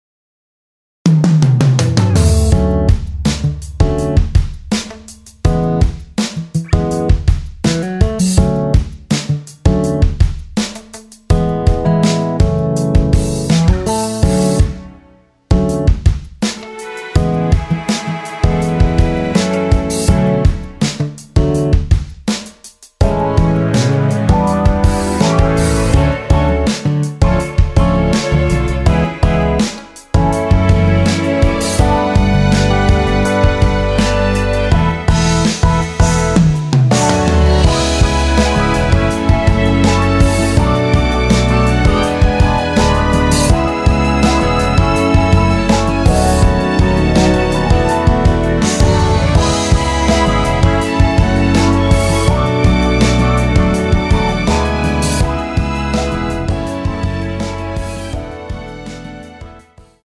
◈ 곡명 옆 (-1)은 반음 내림, (+1)은 반음 올림 입니다.
음정은 반음정씩 변하게 되며 노래방도 마찬가지로 반음정씩 변하게 됩니다.
앞부분30초, 뒷부분30초씩 편집해서 올려 드리고 있습니다.
중간에 음이 끈어지고 다시 나오는 이유는